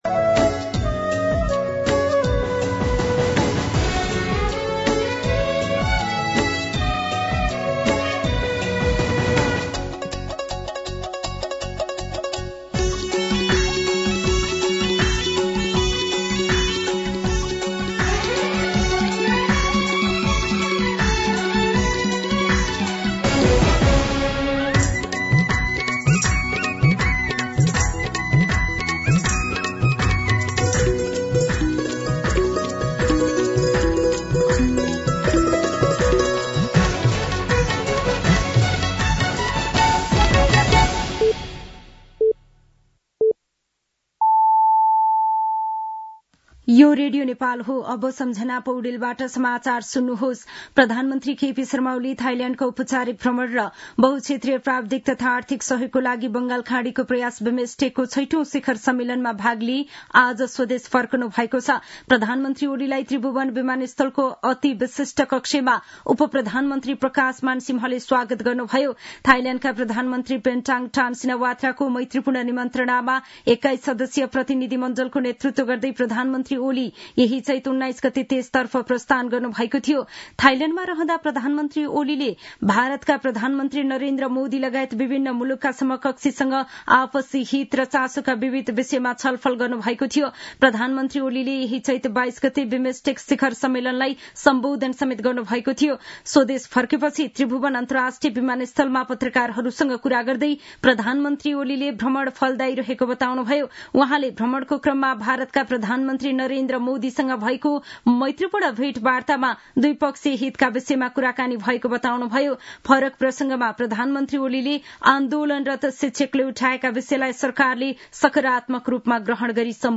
दिउँसो ४ बजेको नेपाली समाचार : २३ चैत , २०८१
4pm-Nepali-News.mp3